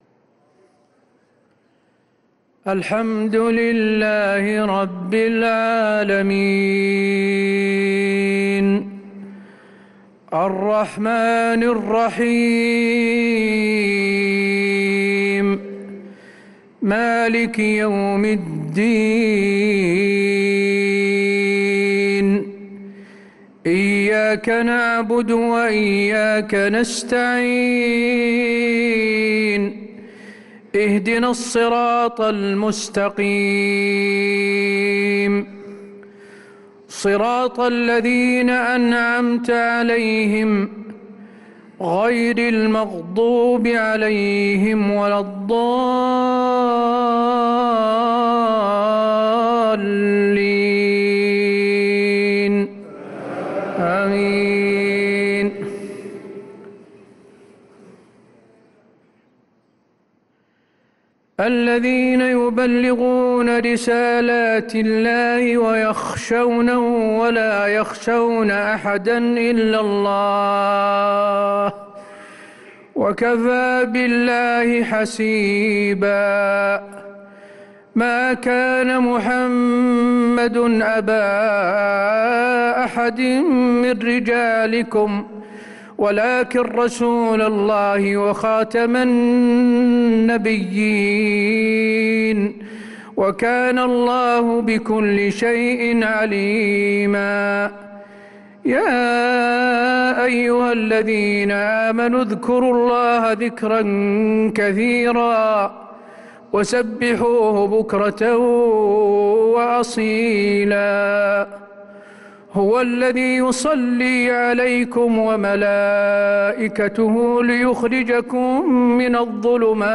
صلاة العشاء للقارئ حسين آل الشيخ 10 رمضان 1445 هـ
تِلَاوَات الْحَرَمَيْن .